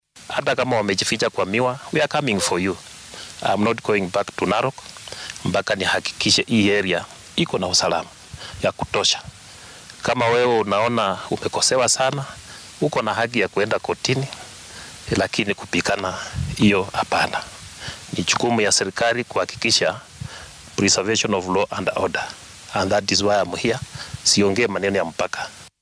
Guddoomiyaha dowladda dhexe u qaabilsan ismaamulka Narok, Issac Masinde ayaa ka hadlay dadka la xiray.